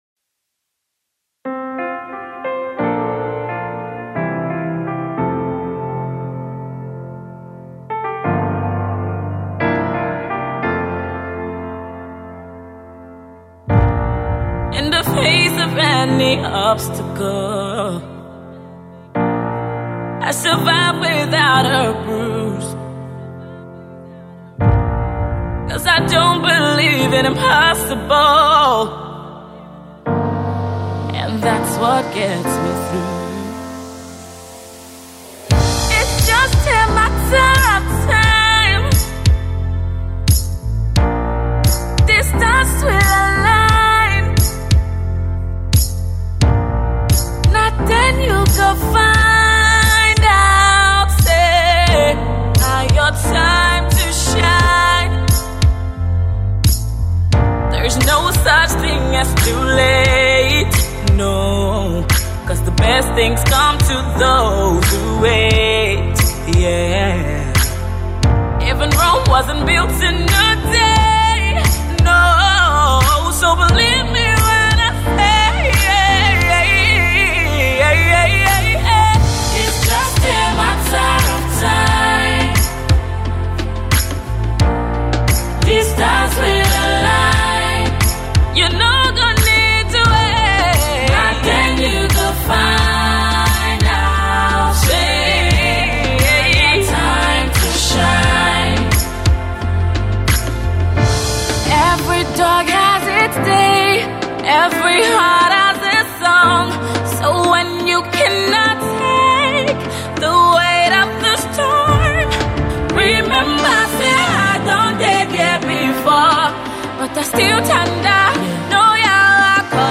soul lifting and exceedingly stimulating song